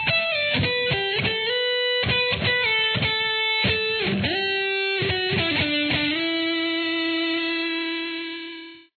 Nice and slow.